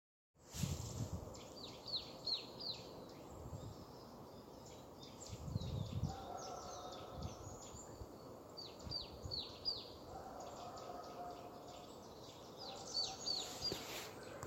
Bird Aves sp., Aves sp.
Administratīvā teritorijaJelgava
StatusVoice, calls heard